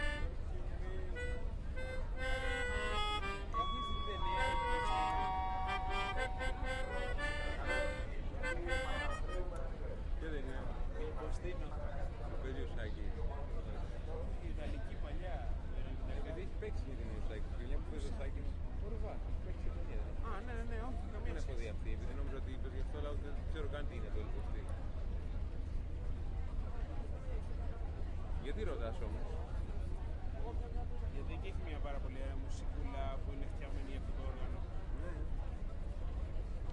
描述：一个希腊朋友在船上演奏他的口琴。
标签： 希腊 口风琴 发动机
声道立体声